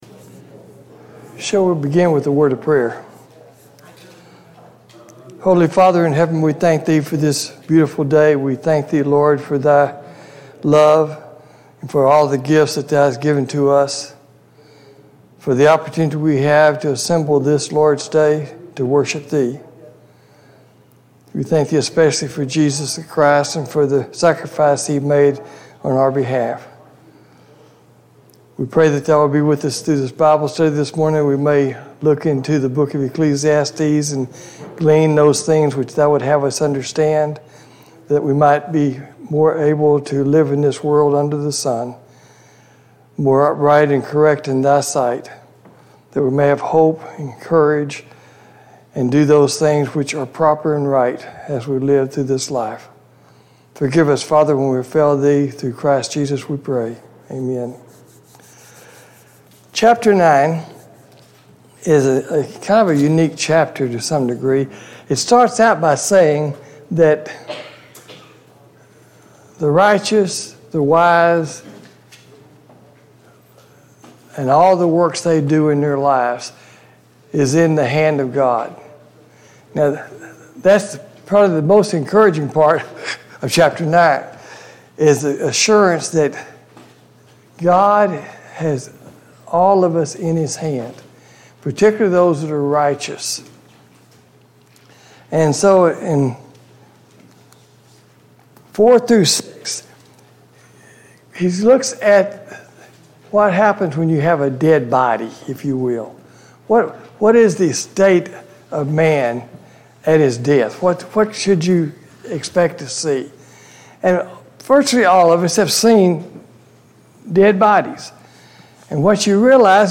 Sunday Morning Bible Class « 4.